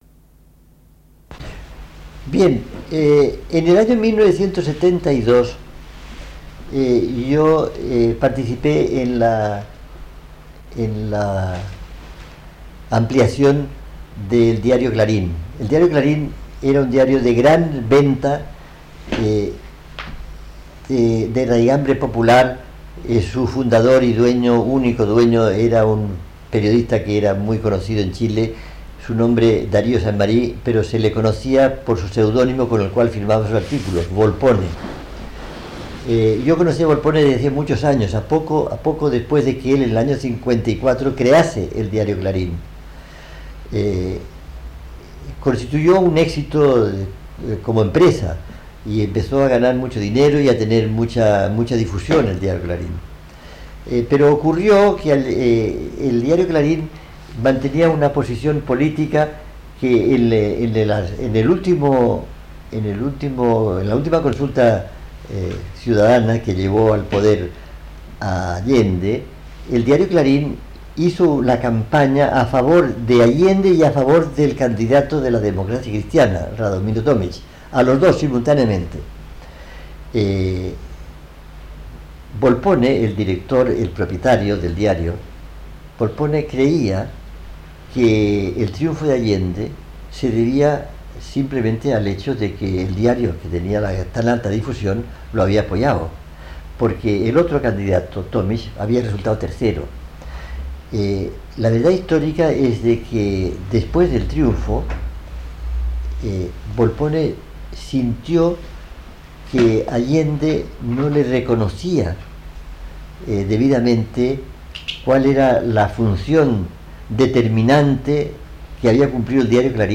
una larga entrevista inédita realizada en Europa